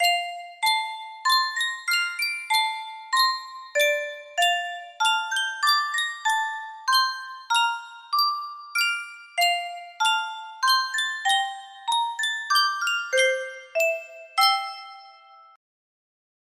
Yunsheng Musikkboks - Kjerringa med Staven 5480 music box melody
Full range 60